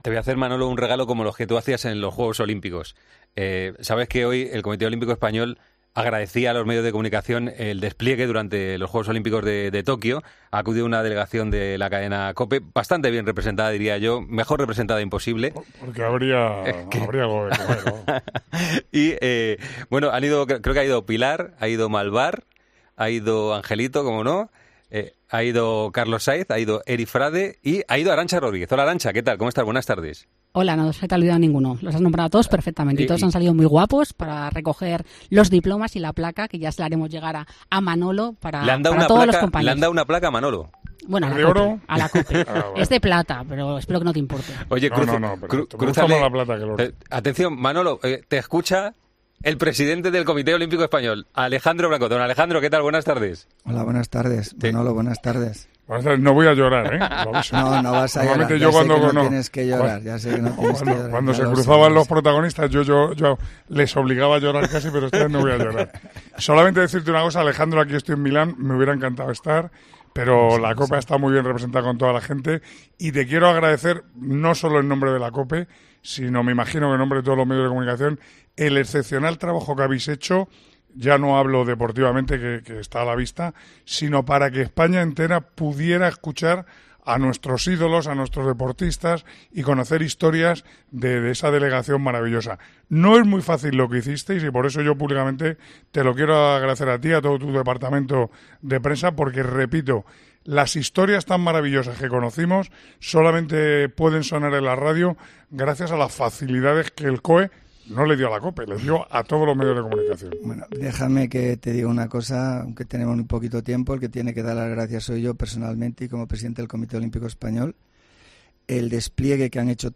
Posteriormente ha pasado por los micrófonos de la Cadena COPE, el propio presidente del COE, para felicitar a la emisora por el trabajo realizado y a Manolo Lama, líder del equipo de enviados especiales: "Felicidades a todo el equipo de la Cadena COPE porque habéis hecho un trabajo extraordinario. Hemos transgredido alguna norma, pero gracias a ello España entera ha tenido la información antes que nadie", ha asegurado Alejandro Blanco.